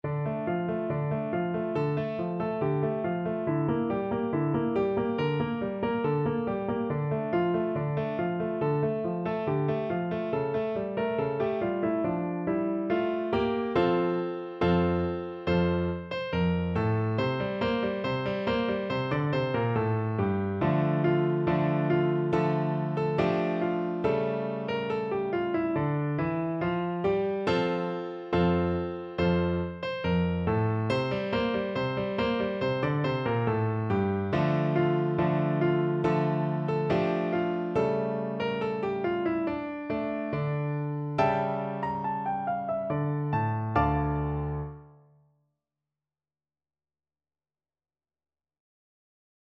Piano version
No parts available for this pieces as it is for solo piano.
4/4 (View more 4/4 Music)
D minor (Sounding Pitch) (View more D minor Music for Piano )
Piano  (View more Easy Piano Music)
Traditional (View more Traditional Piano Music)
world (View more world Piano Music)